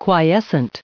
added pronounciation and merriam webster audio
1531_quiescent.ogg